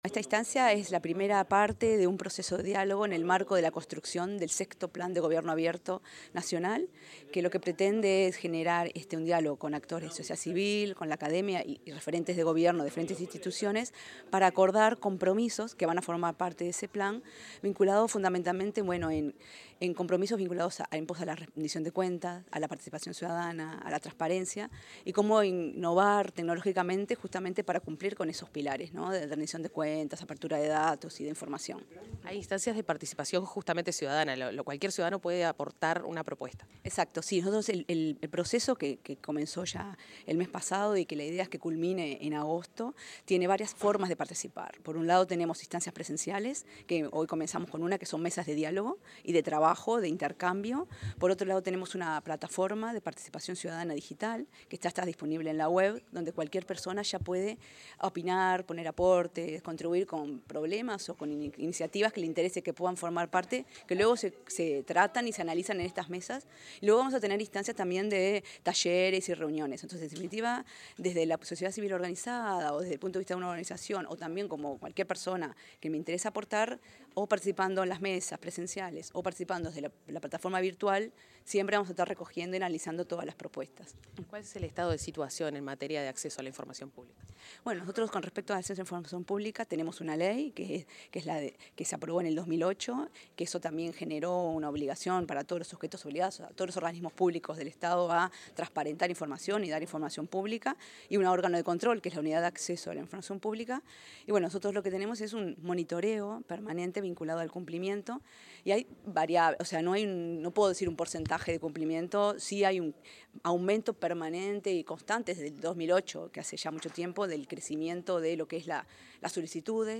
Declaraciones de la directora de Sociedad de la Información de Agesic, Virginia Pardo
Declaraciones de la directora de Sociedad de la Información de Agesic, Virginia Pardo 30/05/2025 Compartir Facebook X Copiar enlace WhatsApp LinkedIn En el marco de la primera mesa de diálogo en el proceso de creación del Sexto Plan Nacional de Gobierno Abierto, la directora de Sociedad de la Información de la Agencia de Gobierno Electrónico y Sociedad de la Información y del Conocimiento (Agesic), Virginia Pardo, brindó declaraciones.